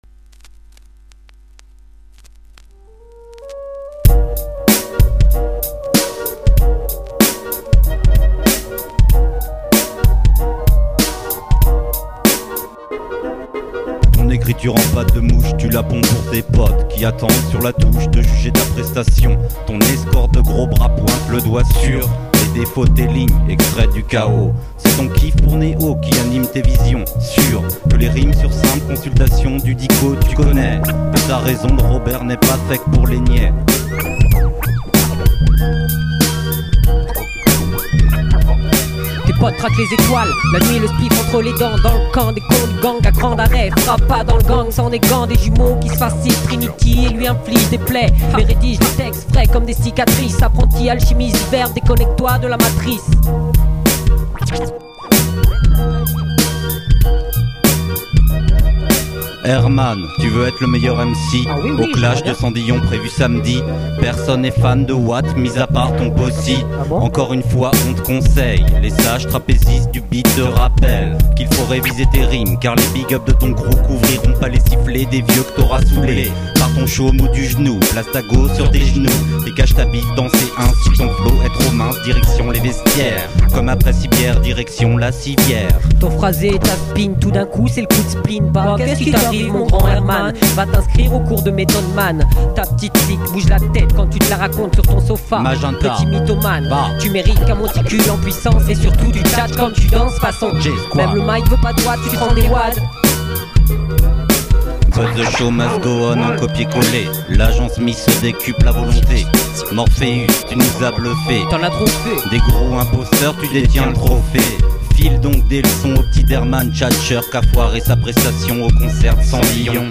genre: rap sans dolby, sans suround